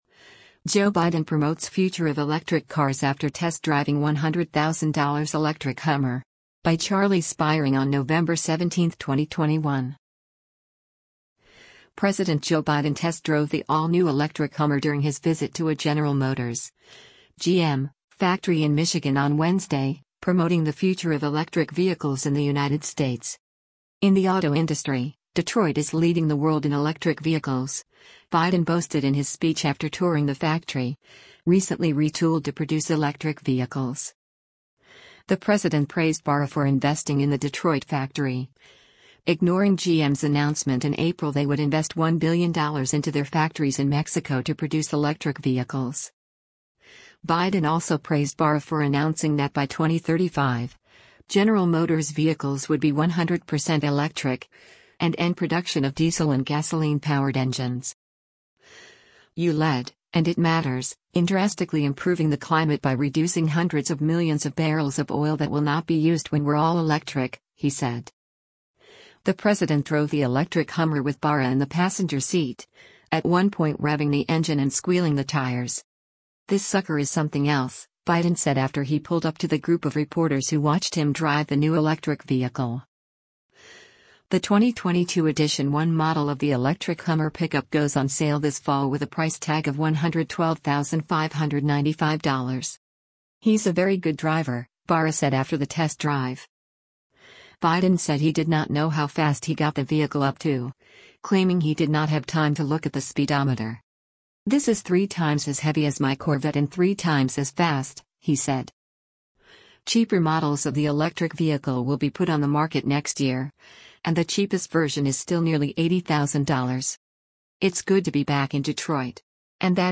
DETROIT, MI - NOVEMBER 17: U.S. President Joe Biden speaks at the General Motors Factory Z
The president drove the electric Hummer with Barra in the passenger seat, at one point revving the engine and squealing the tires.